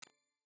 click_short.wav